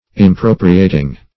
Impropriating - definition of Impropriating - synonyms, pronunciation, spelling from Free Dictionary
Impropriate \Im*pro"pri*ate\, v. t. [imp. & p. p.